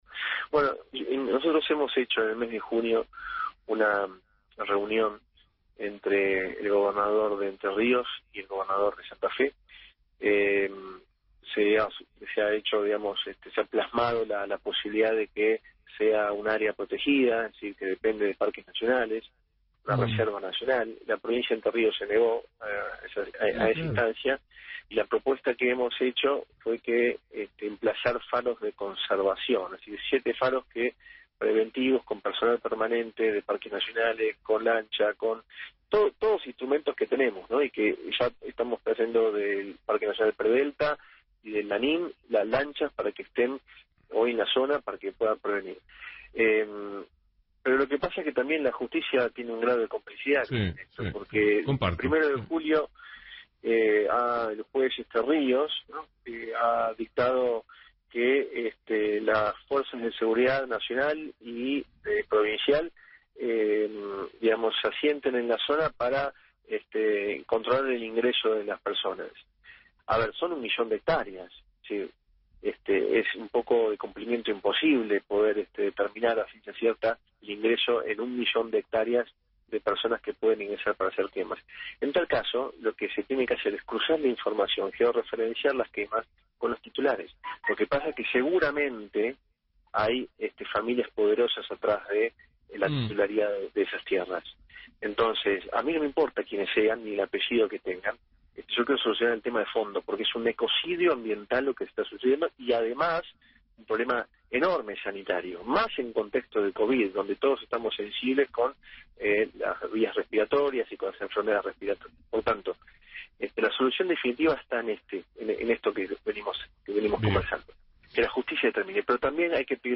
La entrevista más fustigante fue sin dudas la de radio nacional “La Red” en el grama de Luis Novaresio que también se mostró muy crítico a las omisiones de la gestión de Gustavo Bordet para proteger el ambiente.